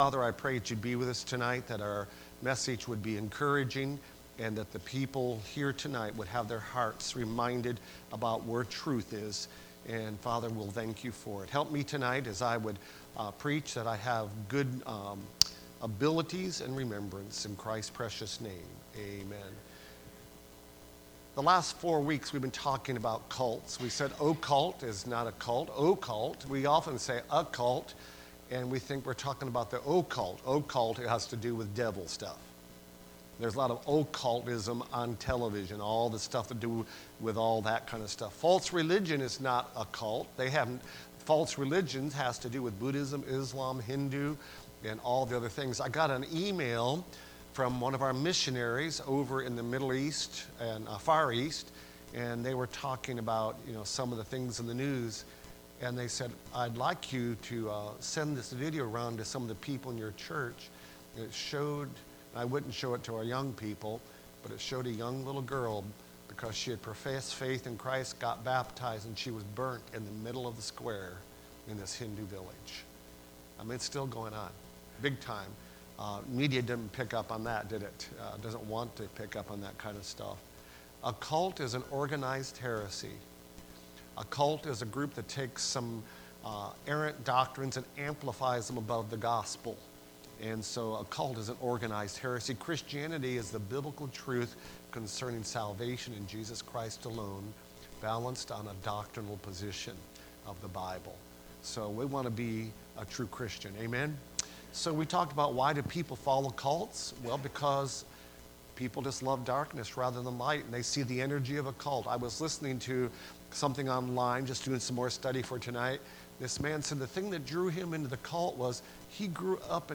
Service Type: Wednesday Prayer Service Preacher